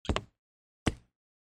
Звук занурення вареників у сметану